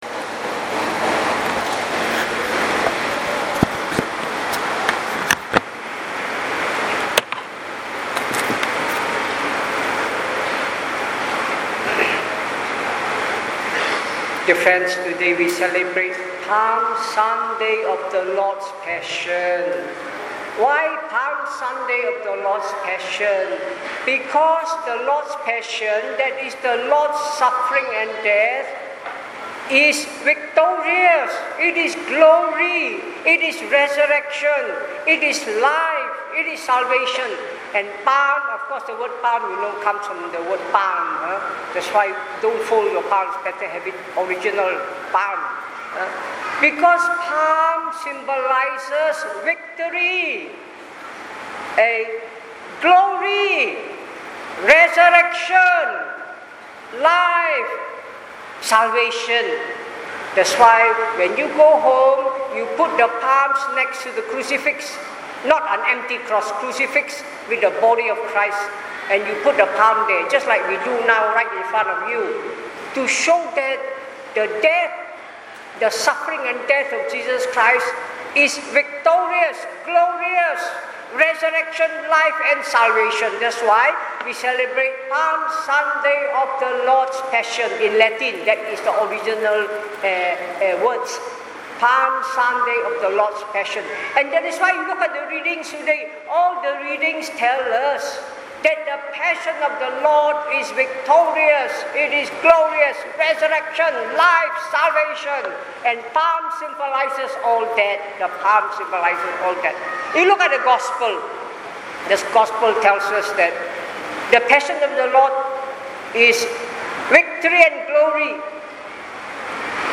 Palm Sunday of The Passion of The Lord (Year C) – 14th April 2019 – English Audio Homily